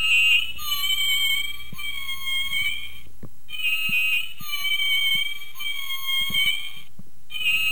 kiwi_call.wav